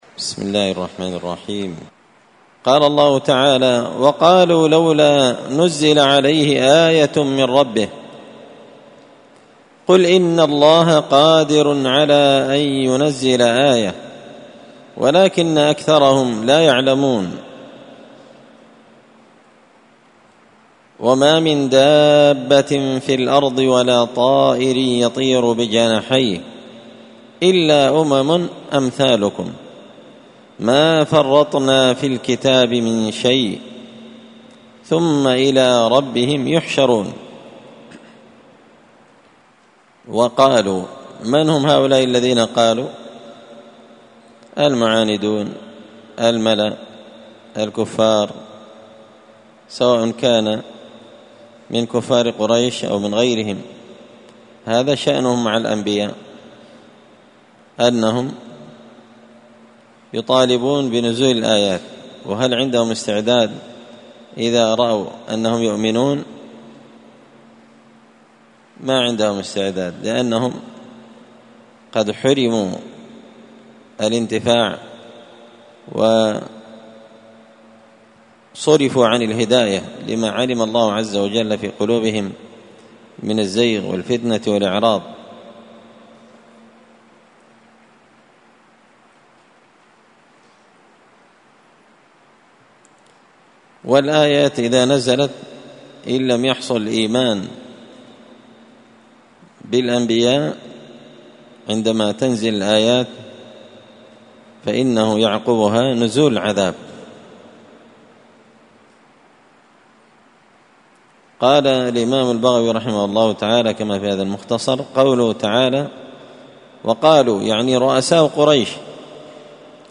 مختصر تفسير الإمام البغوي رحمه الله الدرس 307